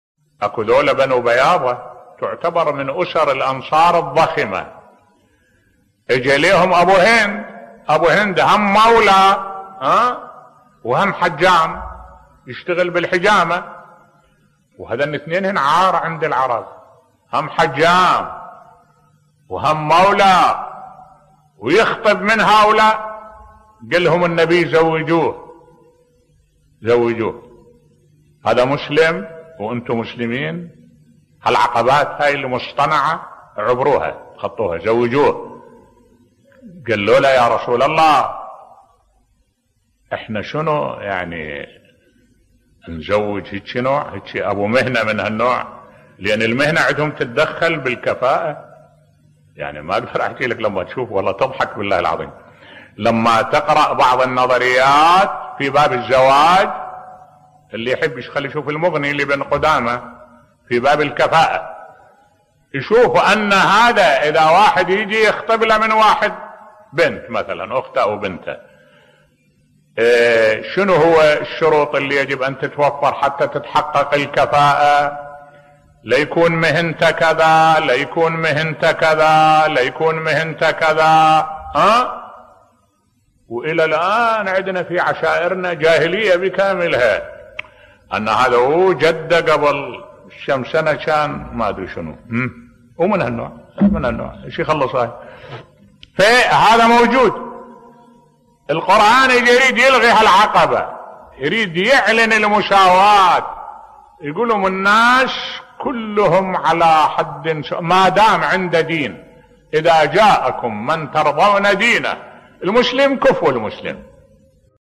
ملف صوتی الإسلام يحترم جميع المهن التي لا تخالف شرع الله بصوت الشيخ الدكتور أحمد الوائلي